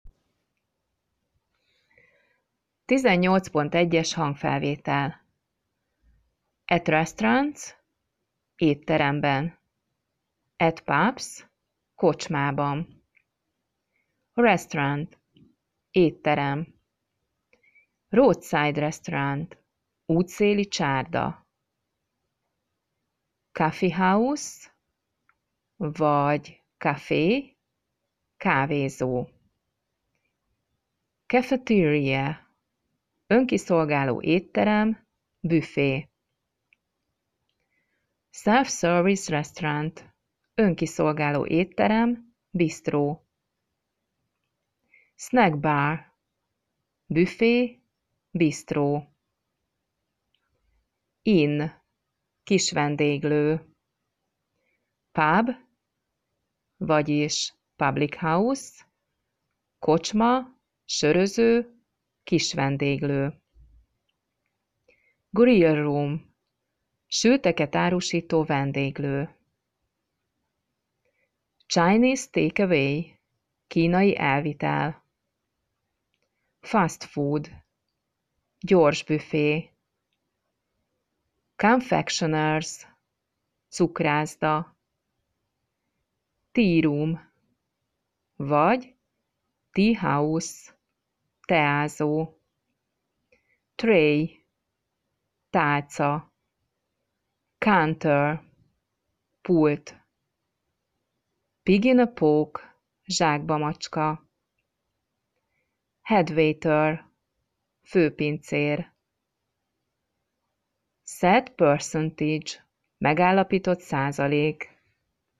lassan, tagoltan, jól artikuláltan, kellemes hangon mondja el a szavakat, kifejezéseket, mondatokat